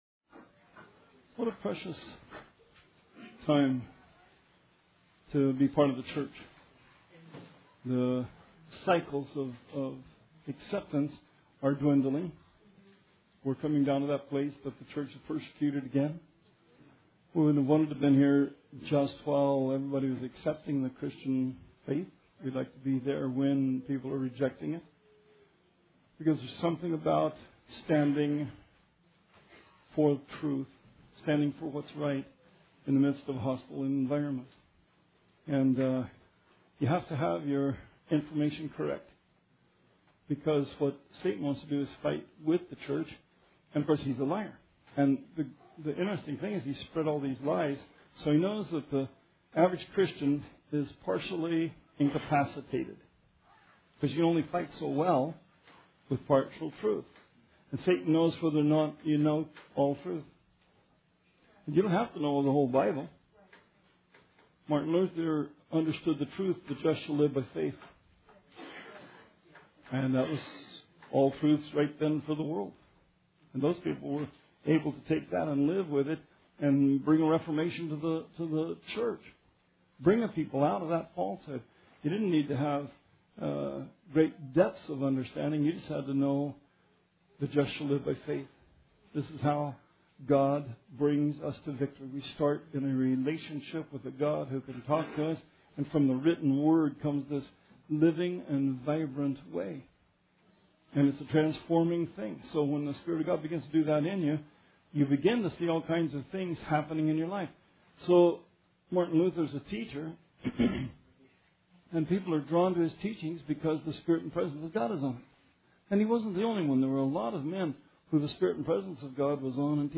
Bible Study 12/21/16